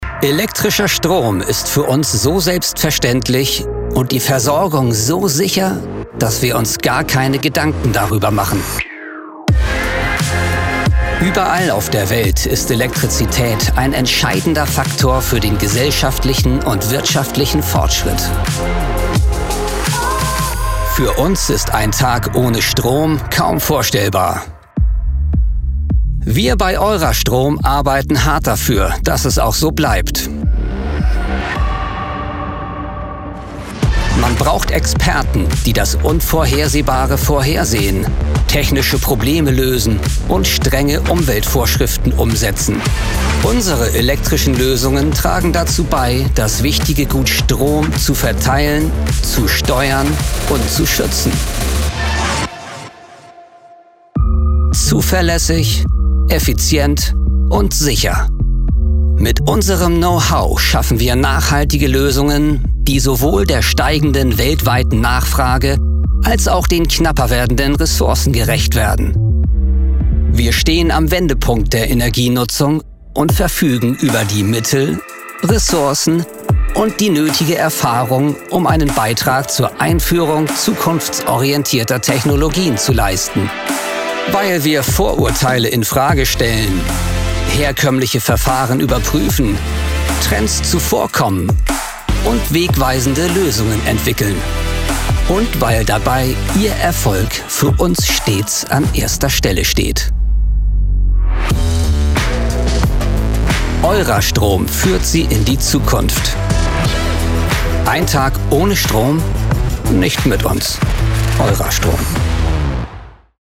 Kommerziell, Cool, Erwachsene, Freundlich, Corporate
Unternehmensvideo